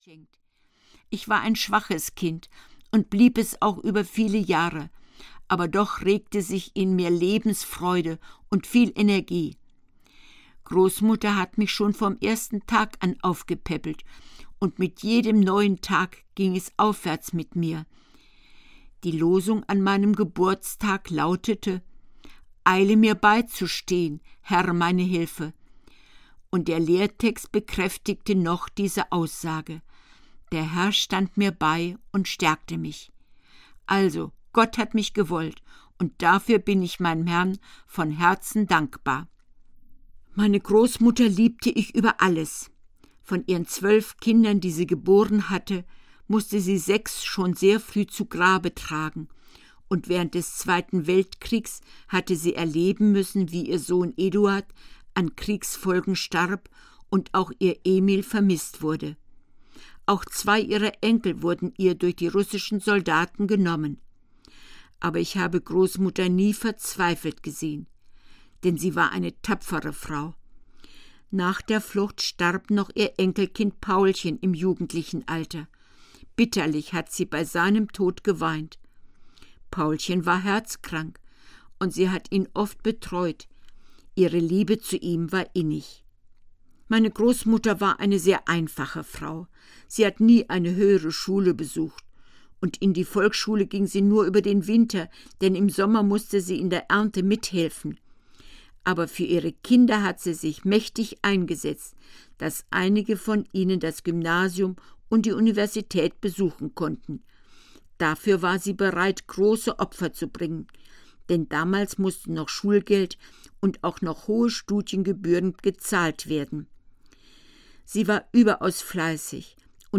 Und doch lacht mir die Sonne - Lotte Bormuth - Hörbuch